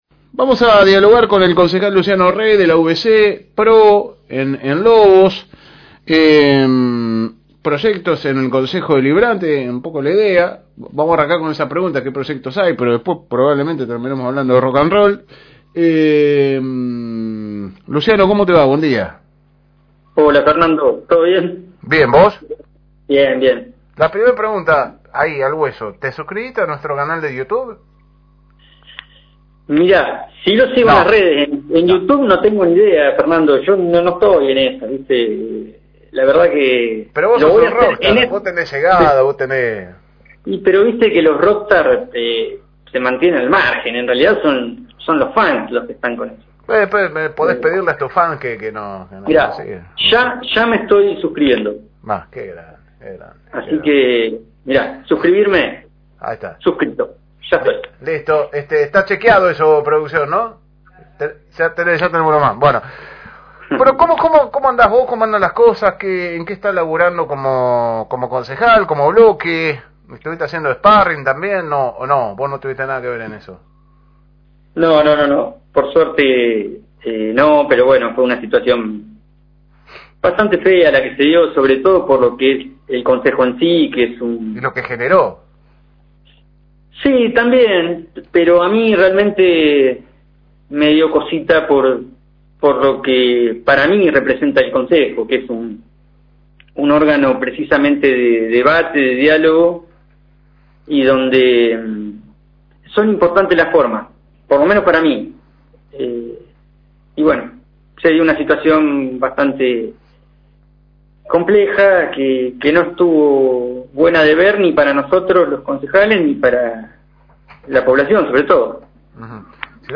Escucha la entrevista entera en el siguiente link y enterate todo sobre la actualidad política de la ciudad.